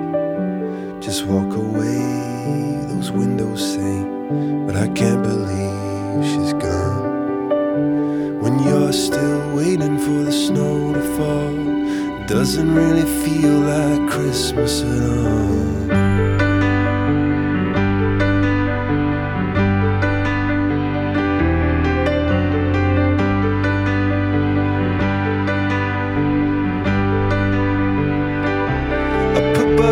alternative rock